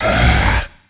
burer_attack_2.ogg